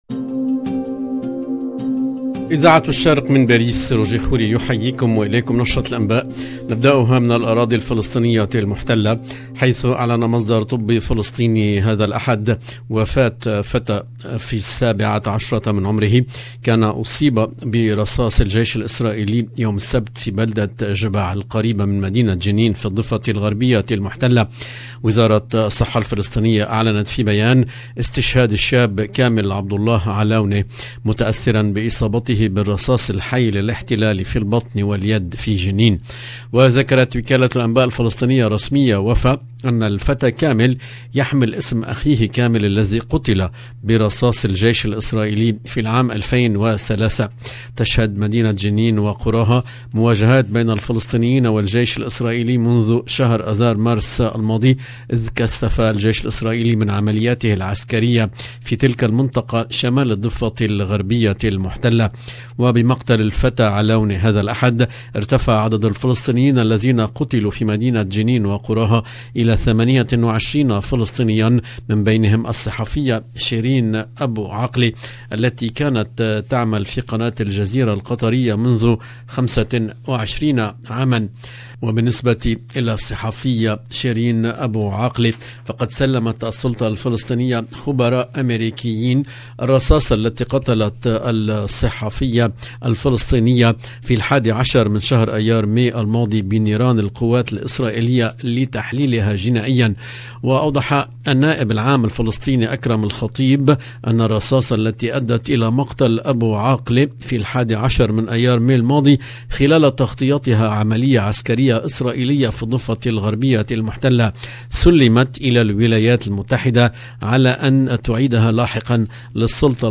LE JOURNAL EN LANGUE ARABE DU SOIR DU 3/07/22